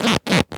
foley_leather_stretch_couch_chair_20.wav